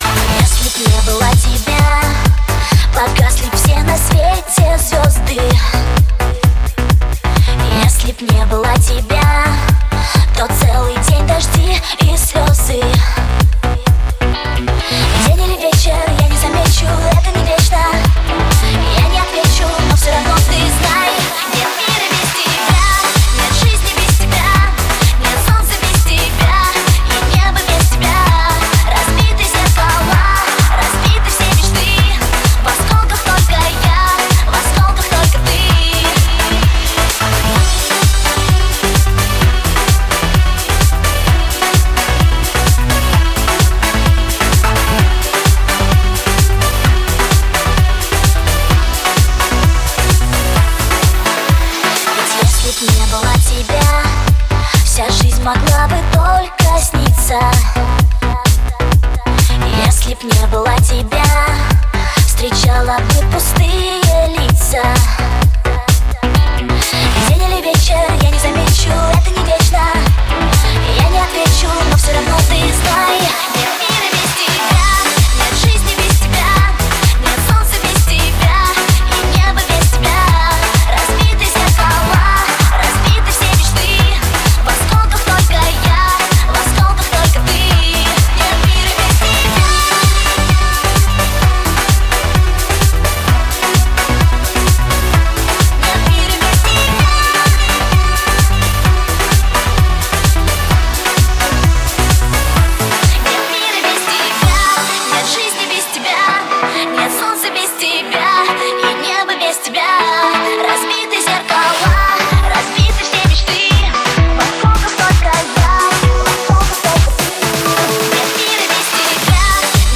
Жанр: Dance music